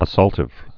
(ə-sôltĭv)